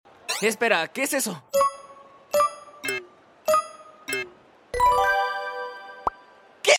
Elliot Maid🍕 FORSAKEN FANDUB ESPAÑOL sound effects free download